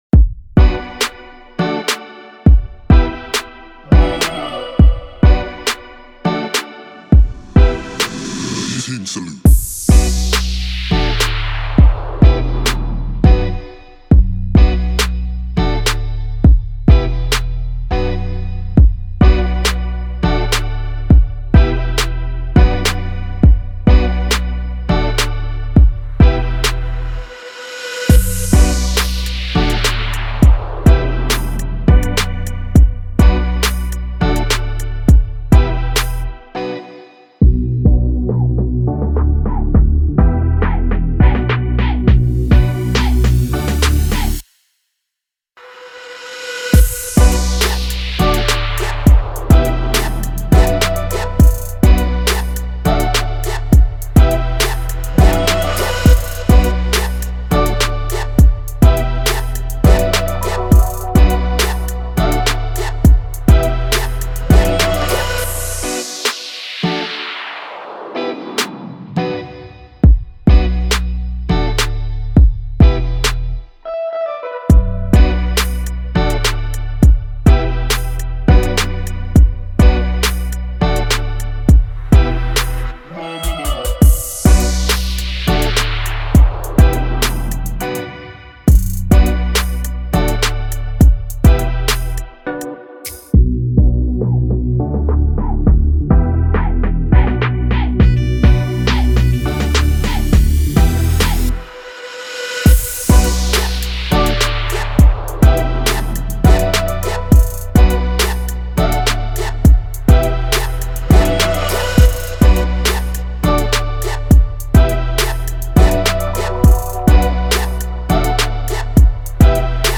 2022 in Dancehall/Afrobeats Instrumentals